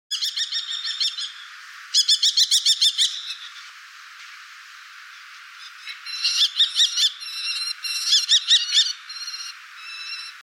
common-kestrel-call.mp3